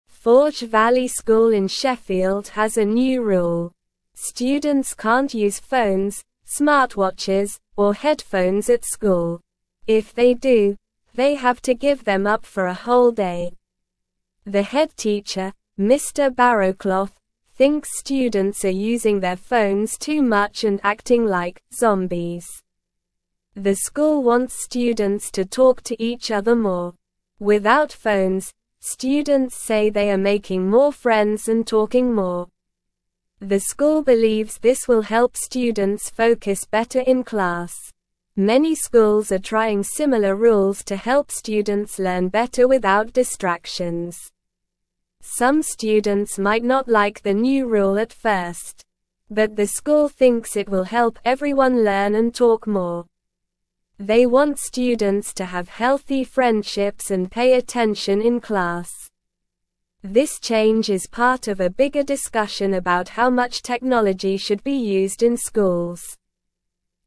Slow
English-Newsroom-Lower-Intermediate-SLOW-Reading-No-Phones-Allowed-at-Forge-Valley-School-in-Sheffield.mp3